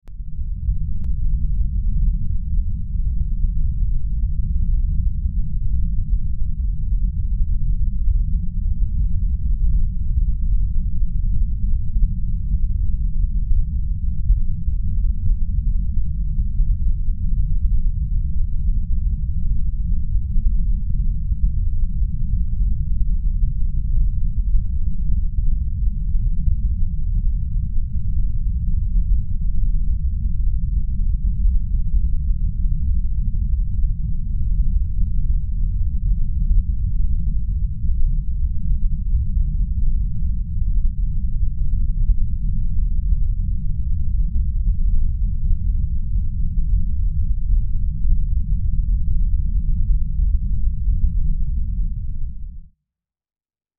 دانلود آهنگ باد 23 از افکت صوتی طبیعت و محیط
جلوه های صوتی
دانلود صدای باد 23 از ساعد نیوز با لینک مستقیم و کیفیت بالا